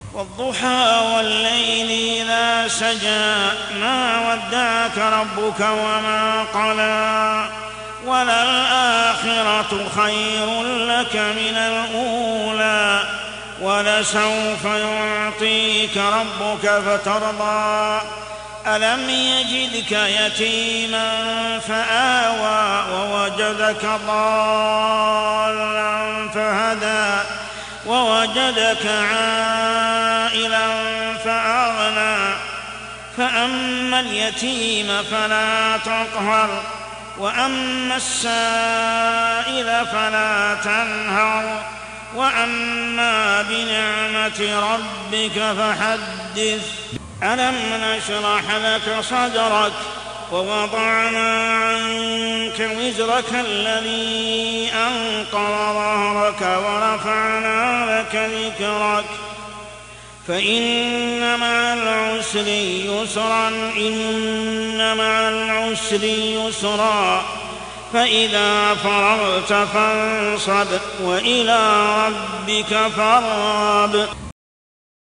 عشائيات شهر رمضان 1424هـ سورتي الضحى و الشرح كاملة | Isha prayer Surah Ad-Duha and Ash-Sharh > 1424 🕋 > الفروض - تلاوات الحرمين